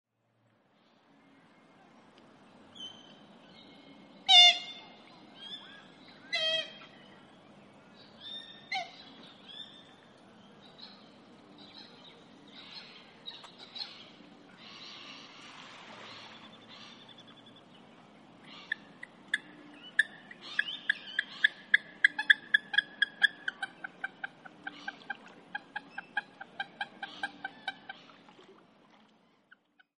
Eurasian Coot - Fulica atra
Voice: loud 'kerk', 'pit-pit' contact calls.
Call 1: 'kerk' calls, then contact calls
Eurasian_Coot1.mp3